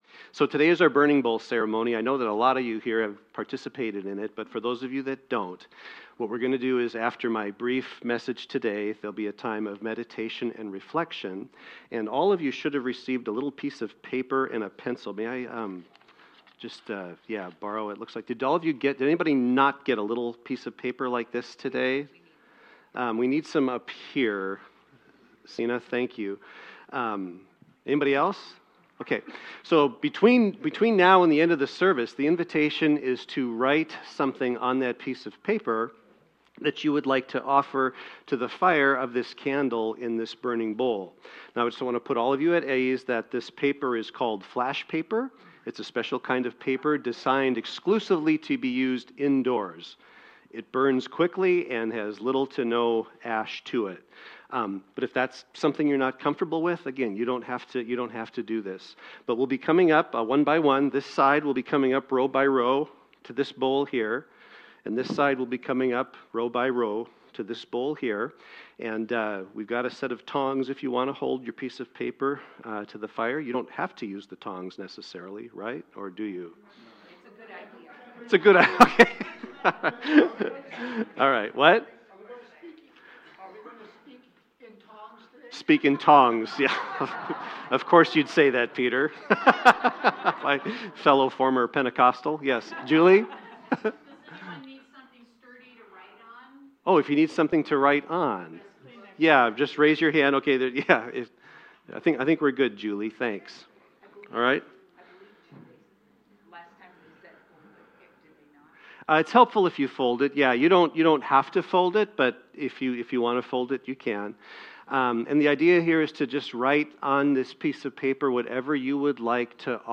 The audio recording below the video clip is an abbreviated version of the service. It includes the Message, Meditation, and Featured Song.